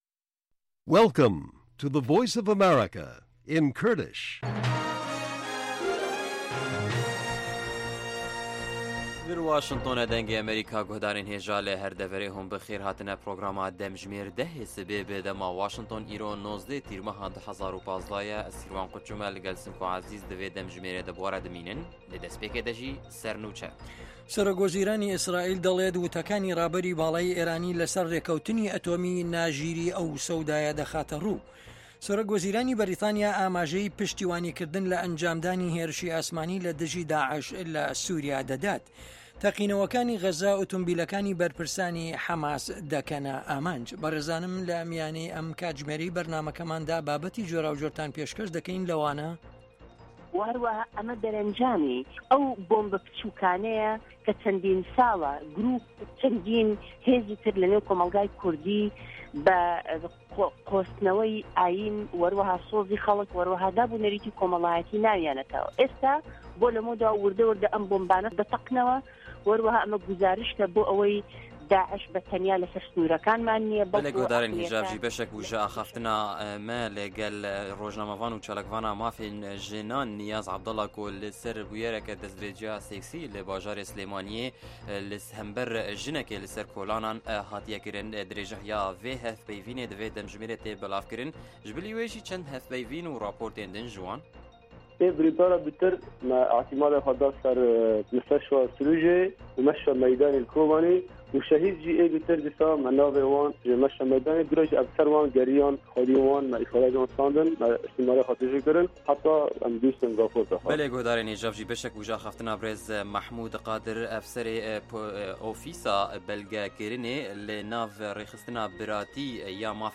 هه‌واڵه‌کان ، ڕاپـۆرت، وتووێژ، مێزگردی هه‌فته‌.